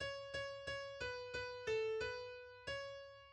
key Bm
transposed -5 from original Em